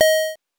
coin_5.wav